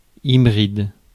Ääntäminen
Synonyymit bâtard Ääntäminen France: IPA: /i.bʁid/ Haettu sana löytyi näillä lähdekielillä: ranska Käännöksiä ei löytynyt valitulle kohdekielelle.